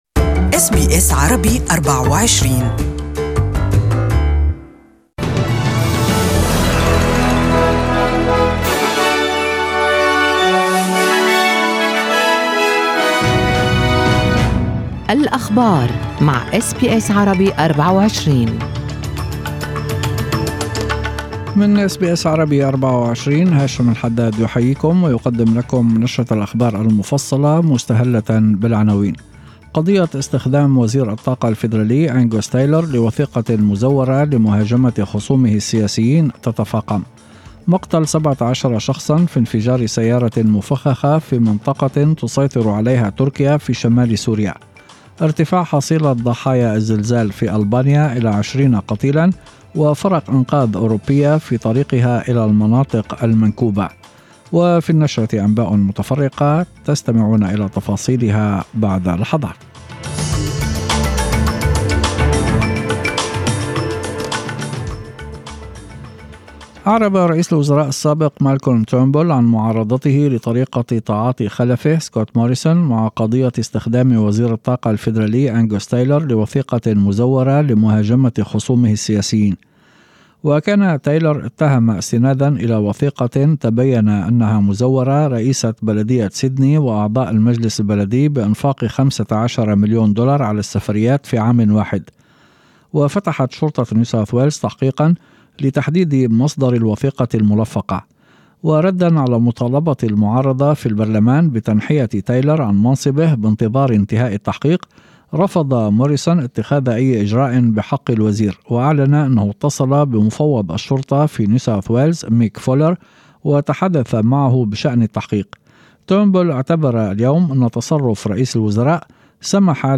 أخبار المساء: وزير يستخدم وثيقة مزورة للهجوم على خصومه والشرطة تحقق